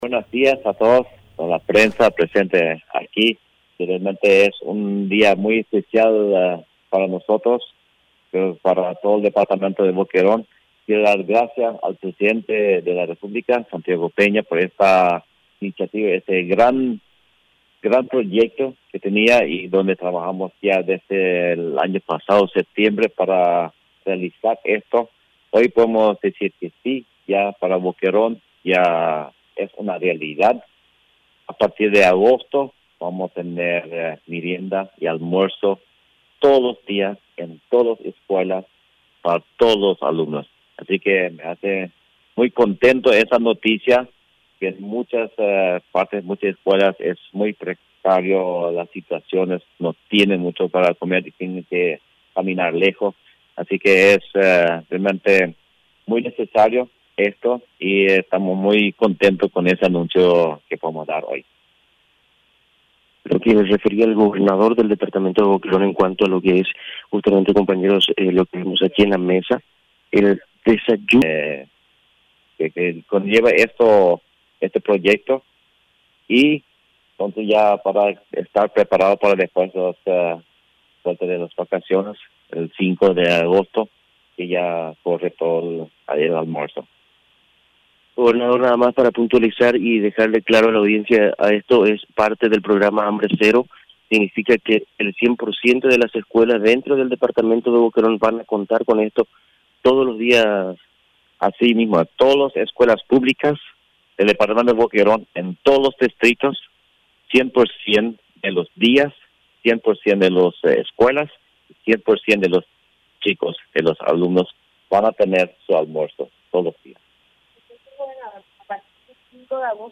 Entrevistas / Matinal 610
Entrevistado: Harold Bergen
Estudio Central, Filadelfia, Dep. Boquerón